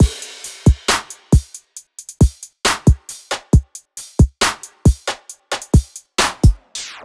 ▼ビートの終わりにエフェクトをつけてみたのがこんな感じです。